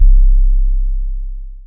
YM Sub 2.wav